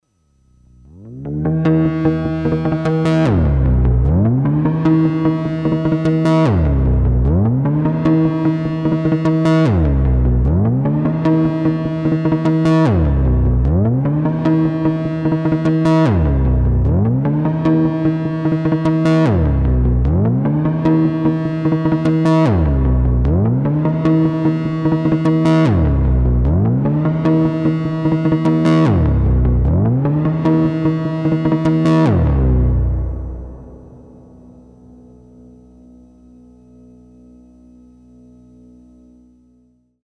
300 BPM is a key but delay is too weak for karplus-Strong.
Siren-like sound is reverb and I have no idea how it works, just happy accident.
No FM, no Impulse trigs :upside_down_face: